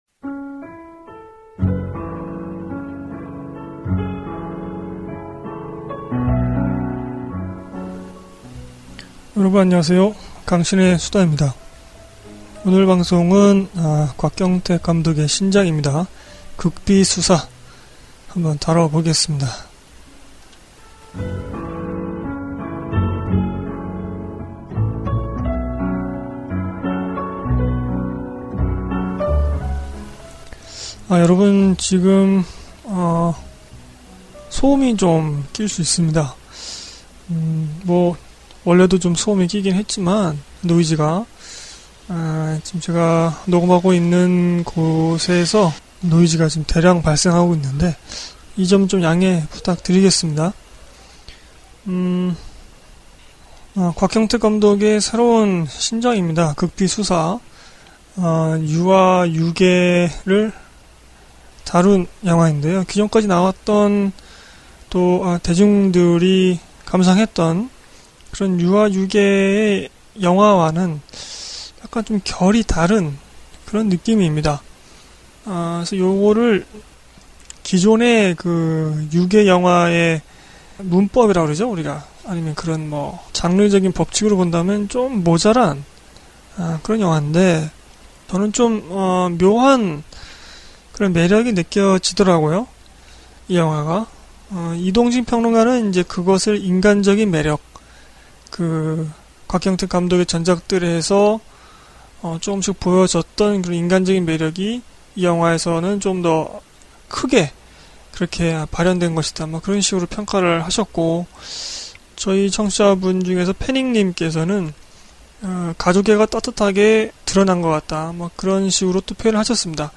* 컨디션이 안 좋은 상태로 녹음했는지라 발음이 많이 뭉개졌습니다.